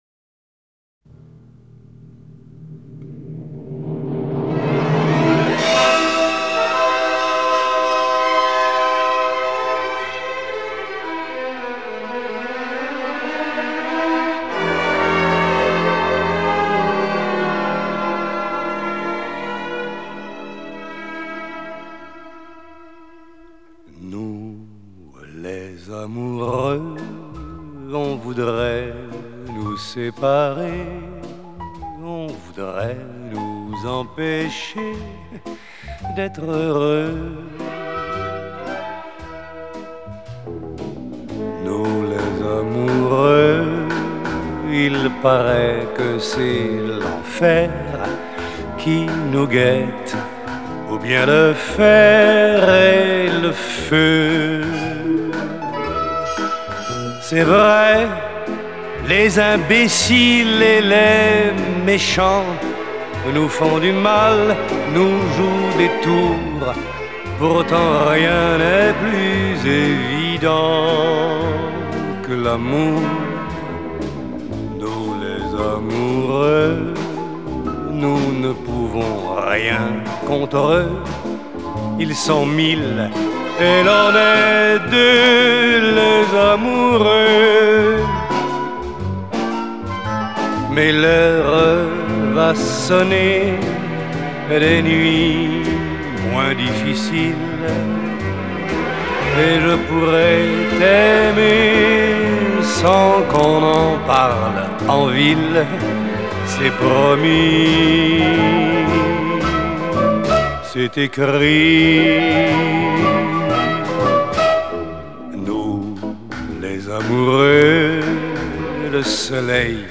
Французская эстрада